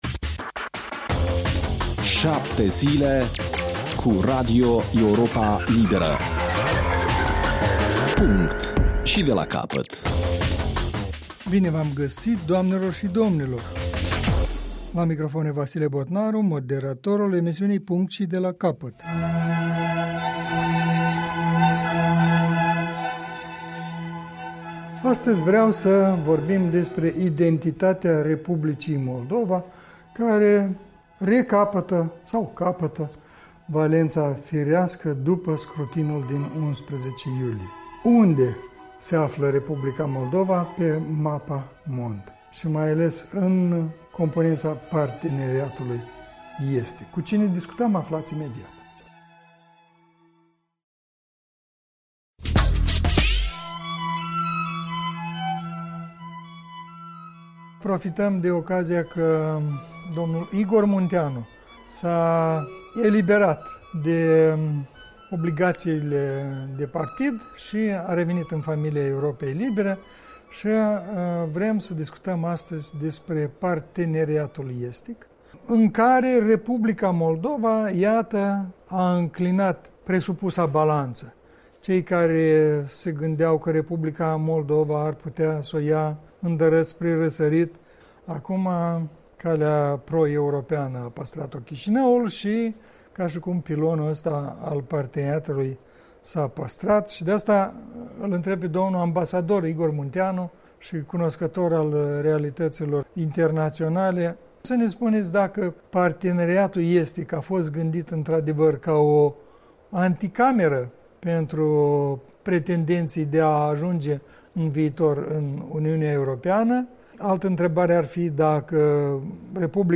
dialog